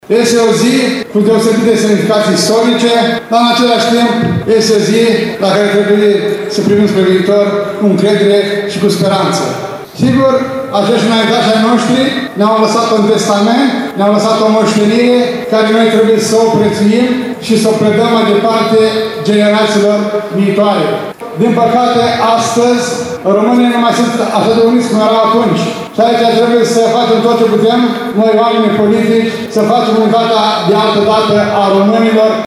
După intonarea imnului național și oficierea unui serviciu religios de către arhiepiscopul Sucevei și Rădăuților PIMEN au urmat alocuțiunile oficialităților.
Primarul municipiului Suceava ION LUNGU.